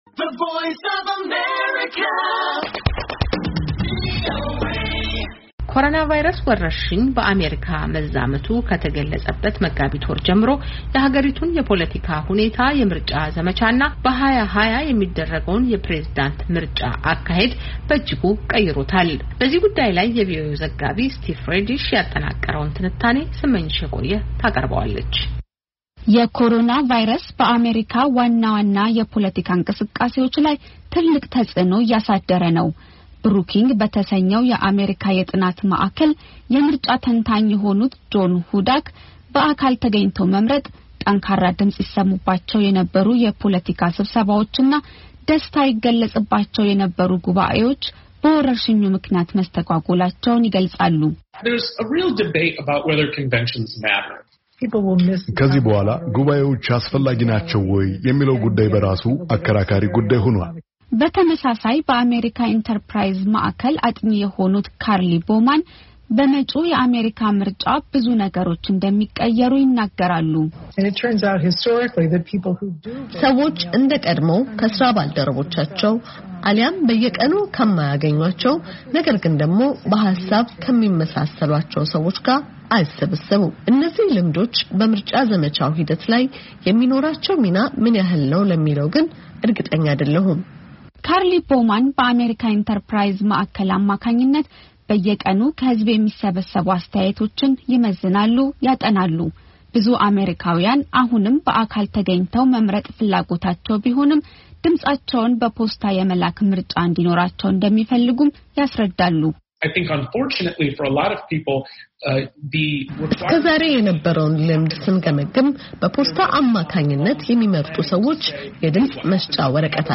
ዘገባ አለ።